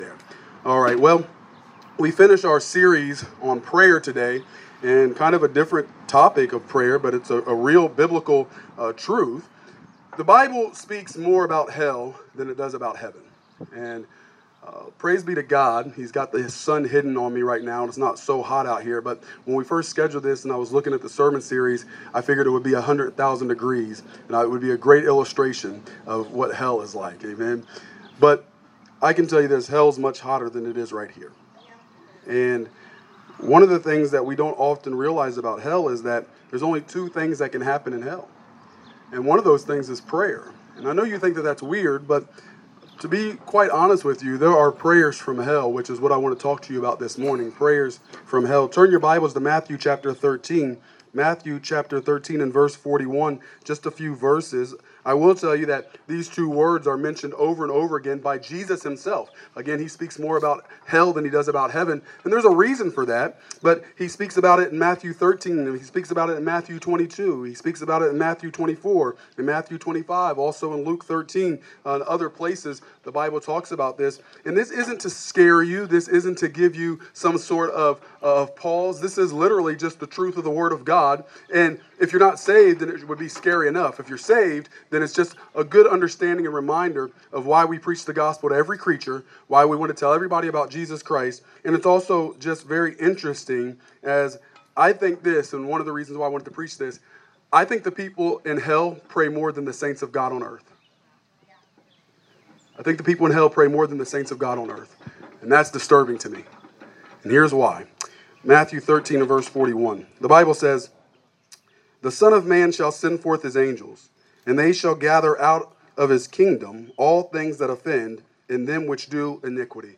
Outside Morning Worship Service